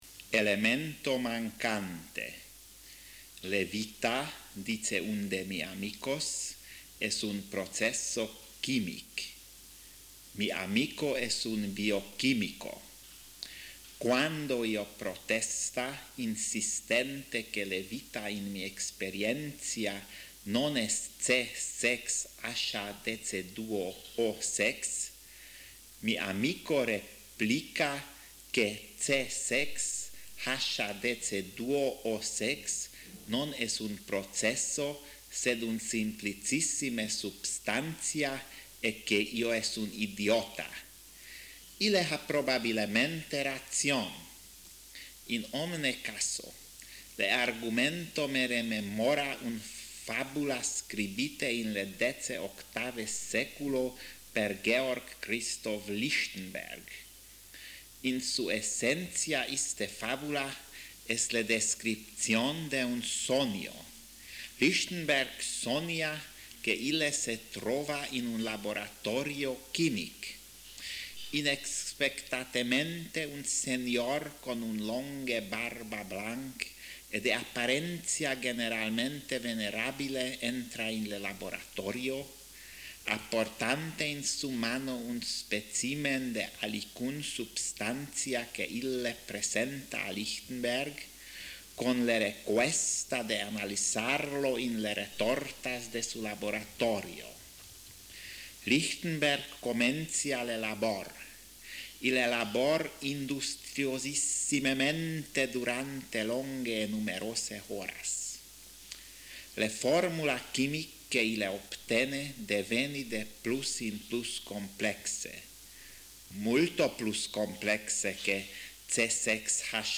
Durante le 7me Conferentia International de Interlingua in Danmark in 1985, un serie de personas registrava le dece contos originalmente seligite per John Lansbury e primo publicate in 1958.